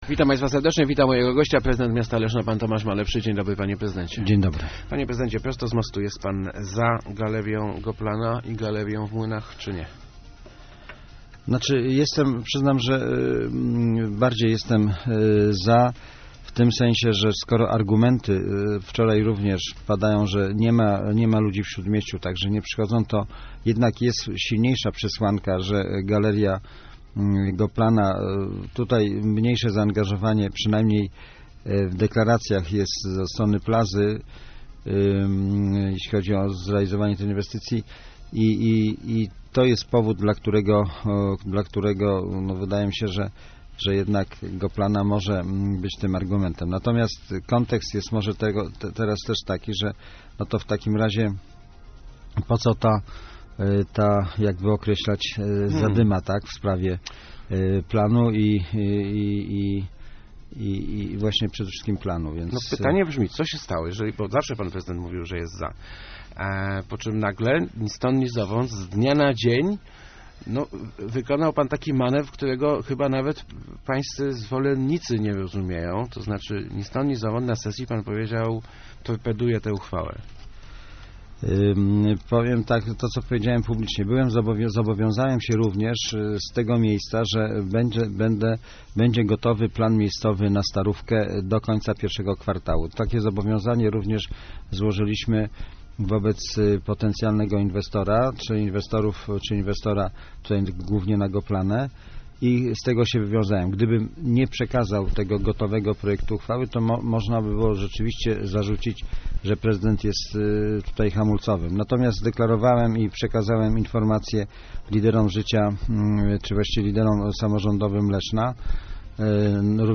Jestem zwolennikiem powstania galerii handlowych w centrum - przekonywa� w Rozmowach Elki prezydent Tomasz Malepszy.